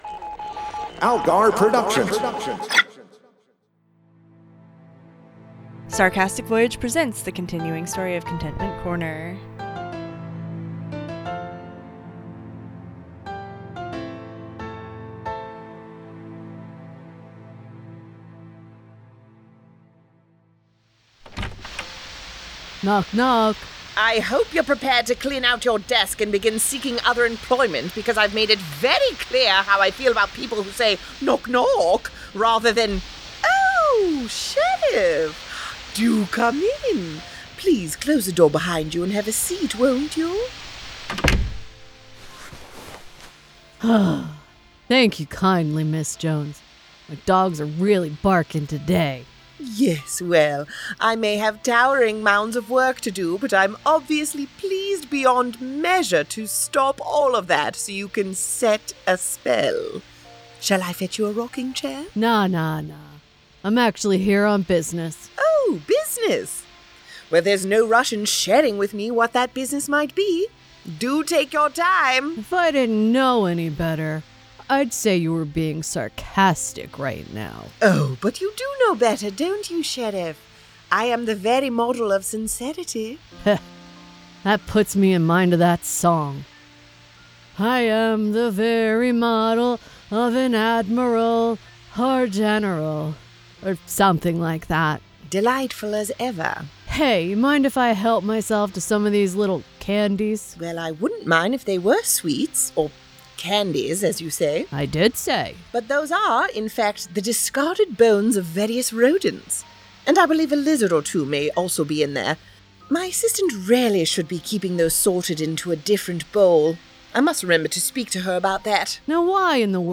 A comedic soap opera.